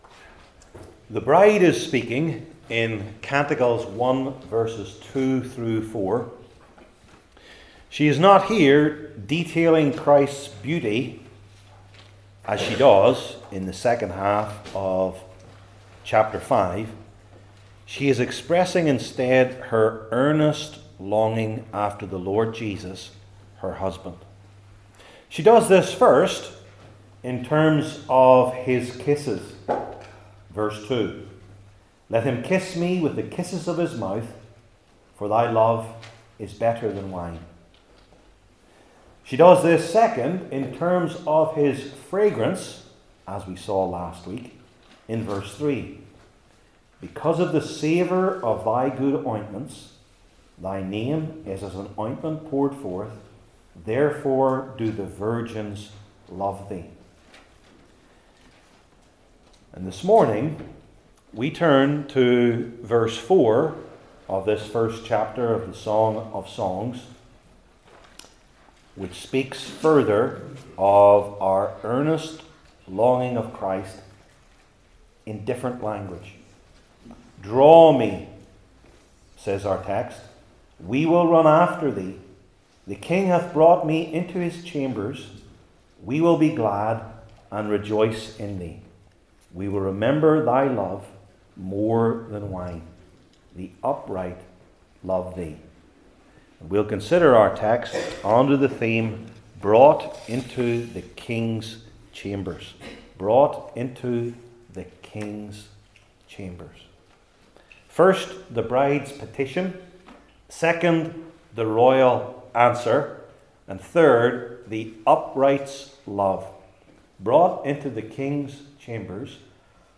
Old Testament Sermon Series I. The Bride’s Petition II.